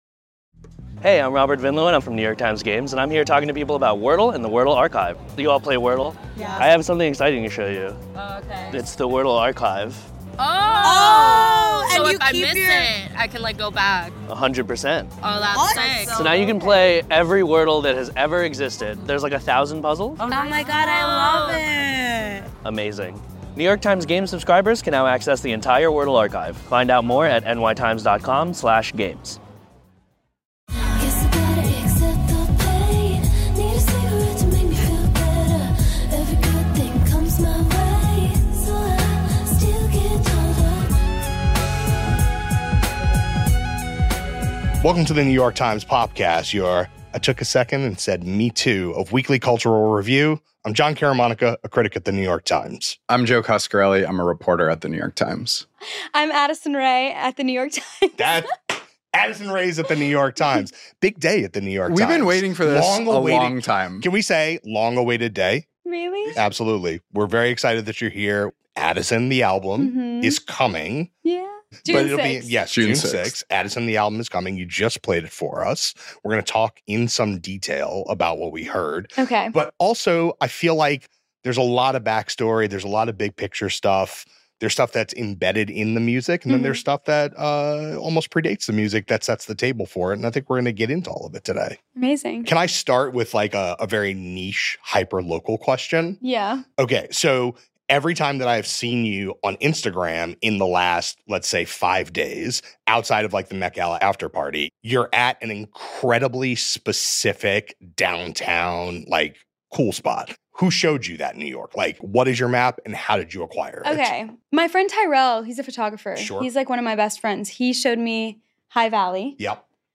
Addison Rae Interview!